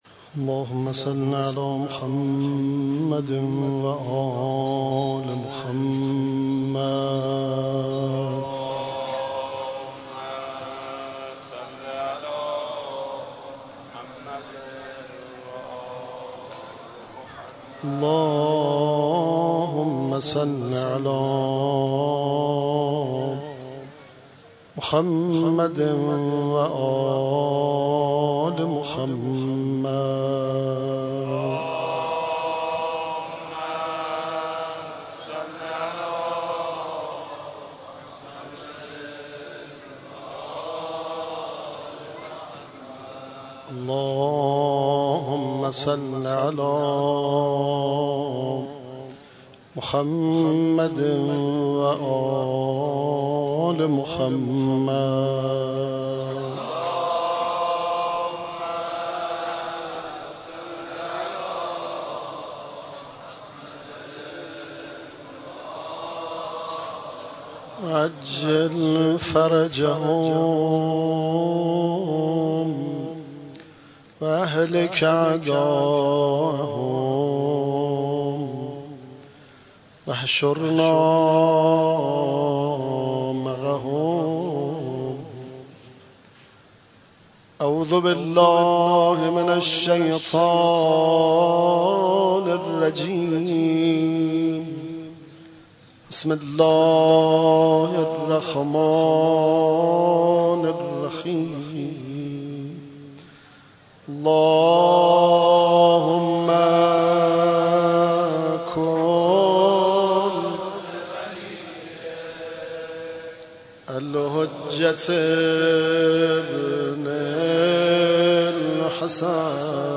قرائت مناجات شعبانیه ، روضه حضرت عباس (علیه السلام)